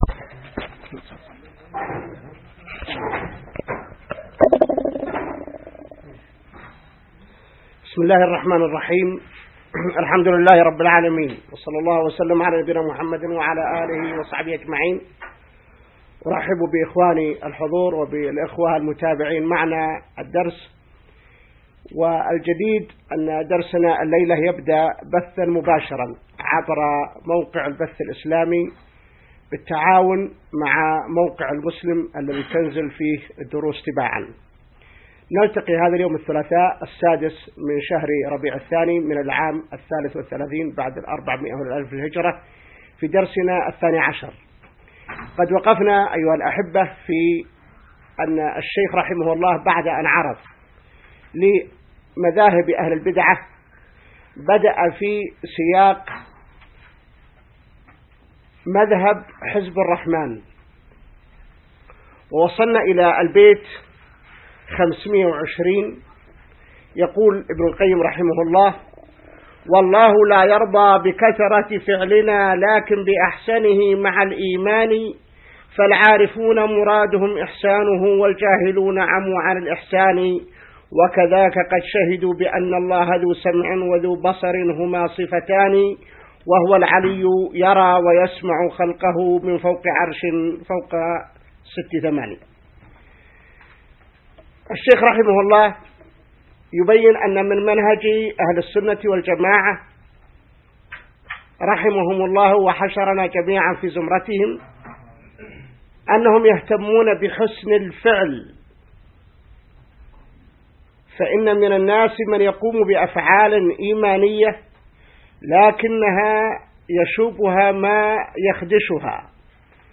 الدرس 12 من شرح نونية ابن القيم | موقع المسلم